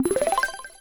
block_destruction.wav